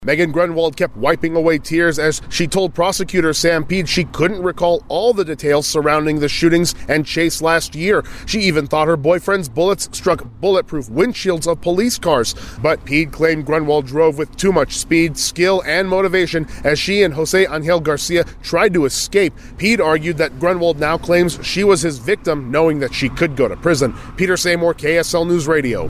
cried, mumbled her way through cross-examination